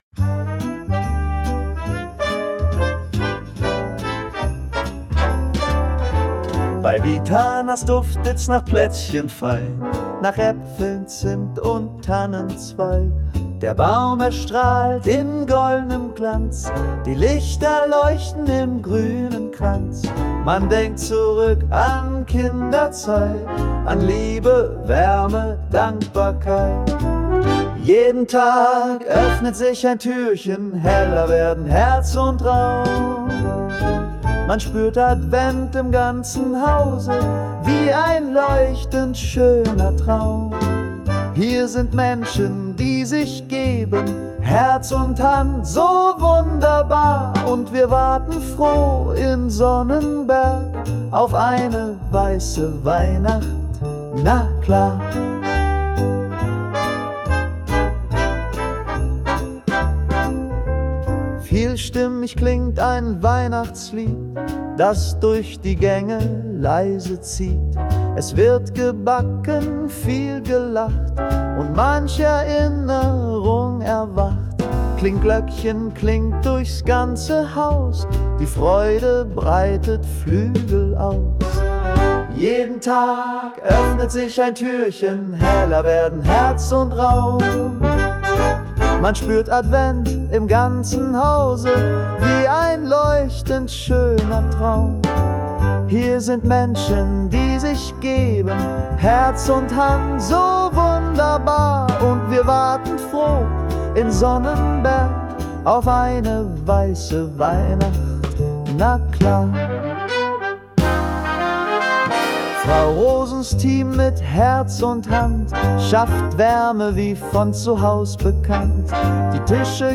Nach Ihrem Briefing und Input erstelle ich Konzept, Text und Sounddesing für Ihren Song, die Einspielung erfolgt dann durch KI-Softwaretools.